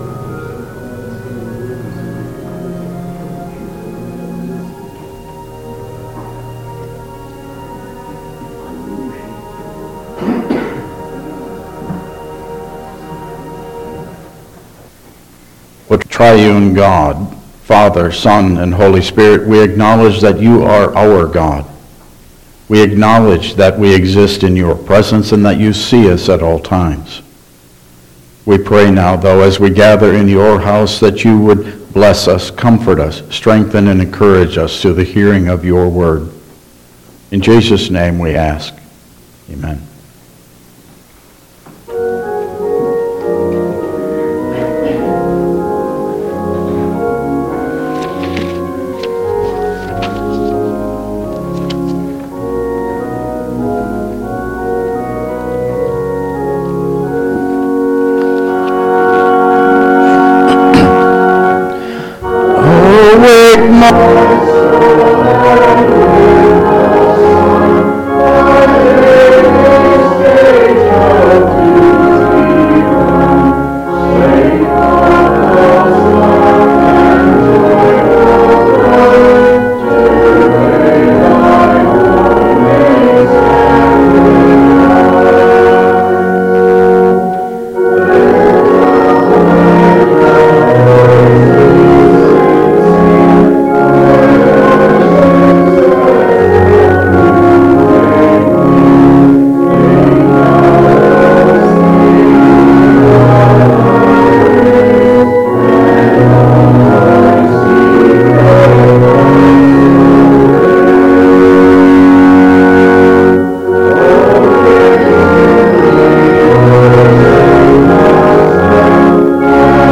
Passage: Mark 10:2-16 Service Type: Regular Service